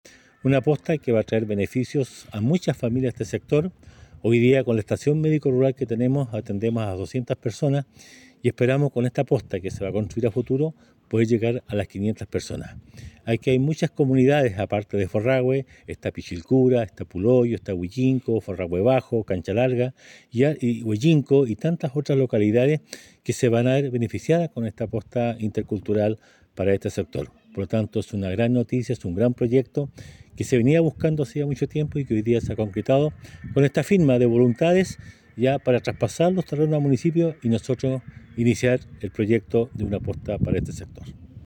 19-agosto-23-emeterio-carrillo-posta.mp3